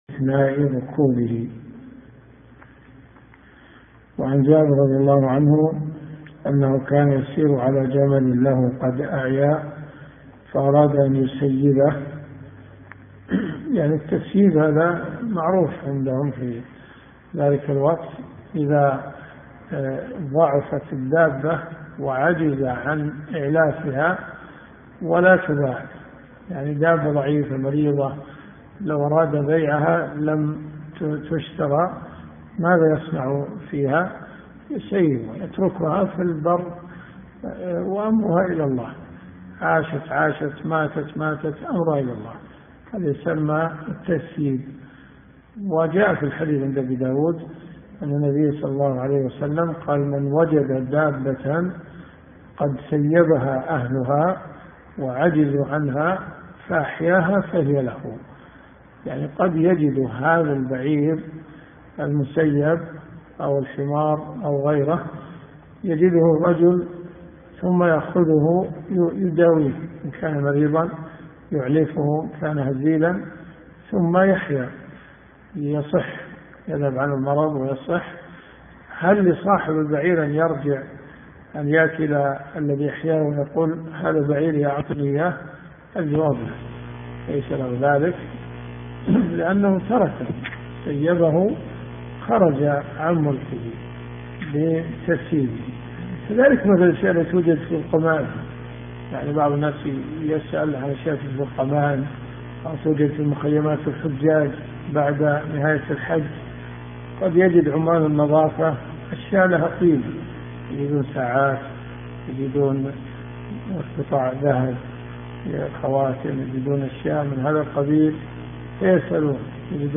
دروس صوتيه
اذا الصوت ضعيف استخدم سماعة الاذن